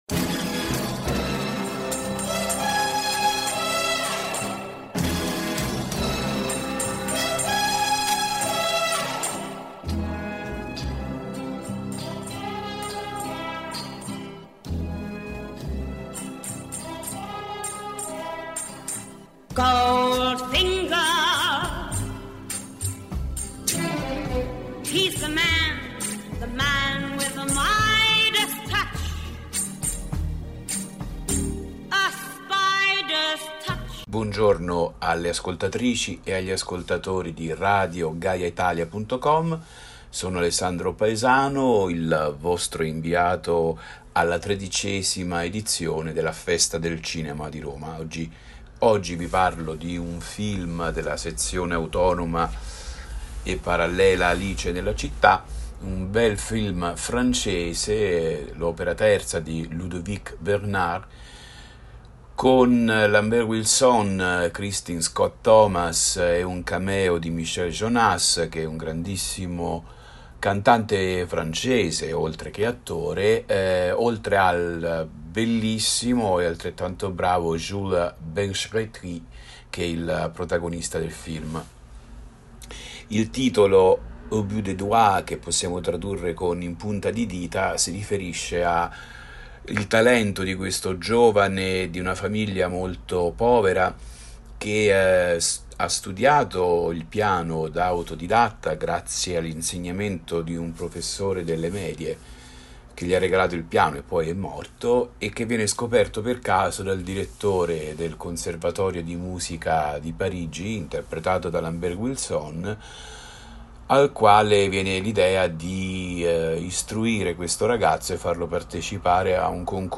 dalla Festa del Cinema di Roma